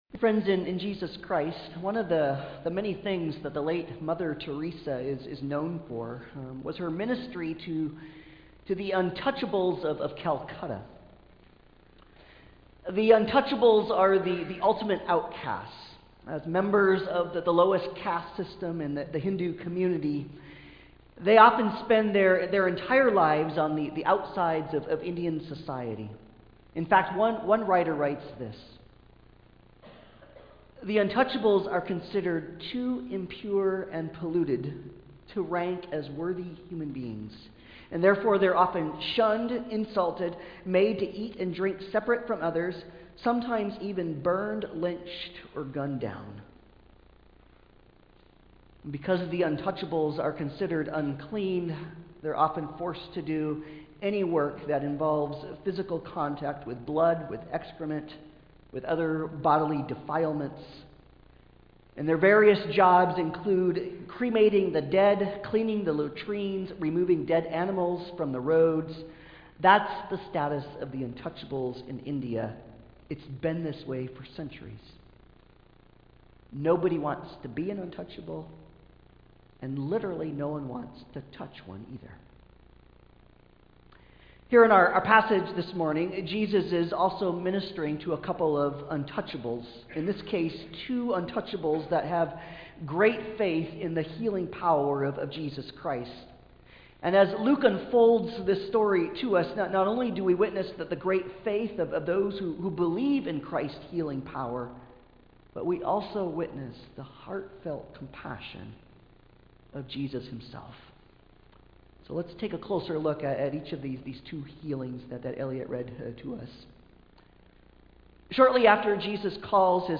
Passage: Luke 5: 12 - 26 Service Type: Sunday Service